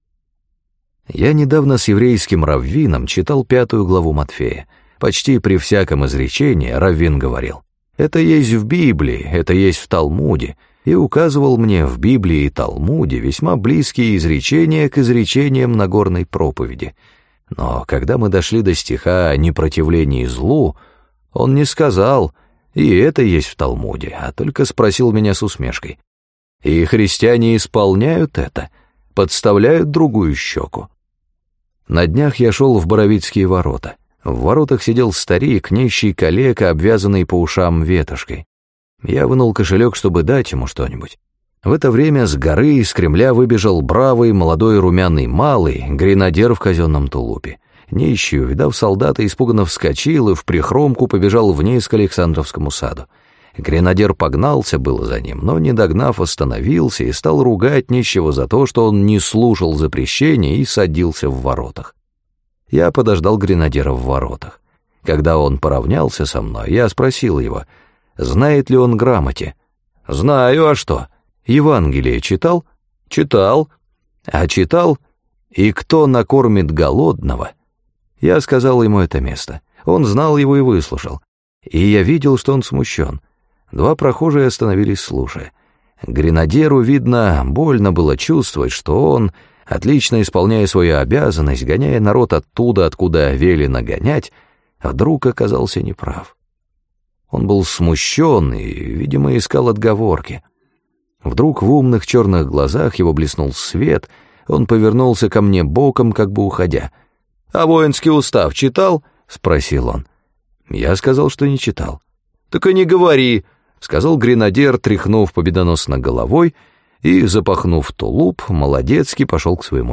Аудиокнига В чем моя вера?
Прослушать и бесплатно скачать фрагмент аудиокниги